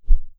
Close Combat Swing Sound 20.wav